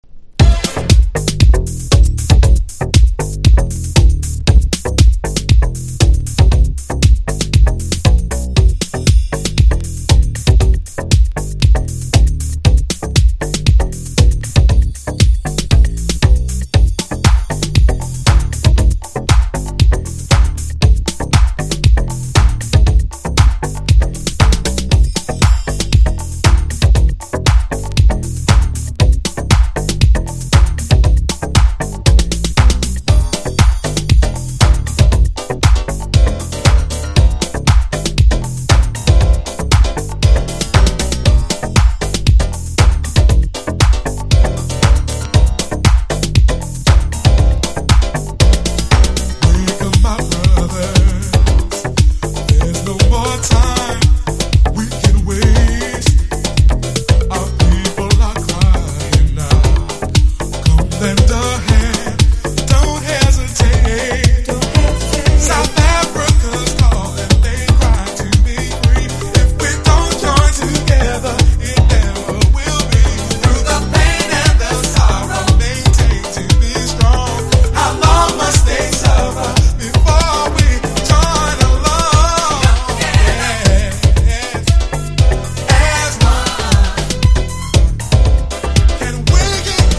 形式 : 12inch (A) / 型番 : / 原産国 : USA